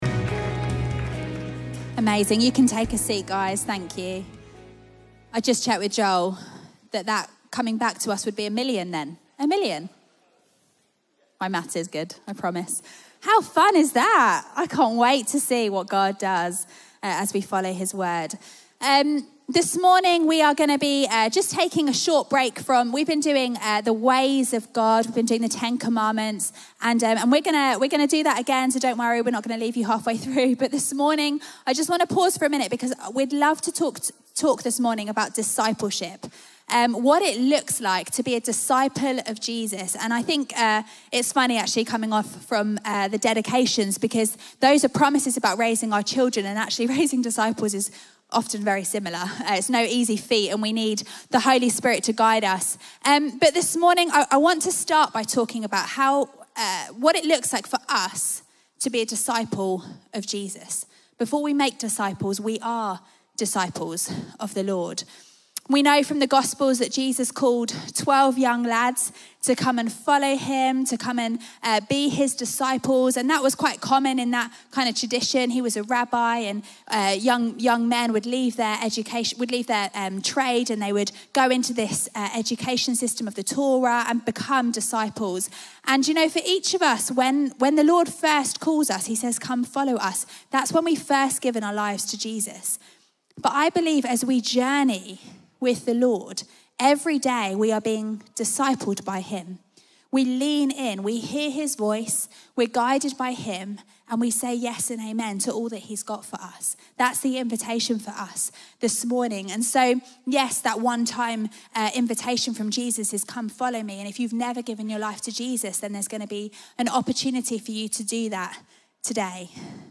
Chroma Church Live Stream
Sunday Sermon Discipleship